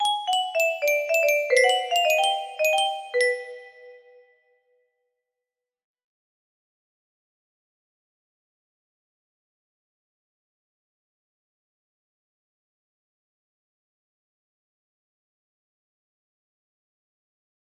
Pretty Fields music box melody